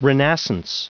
Prononciation du mot renascence en anglais (fichier audio)
Prononciation du mot : renascence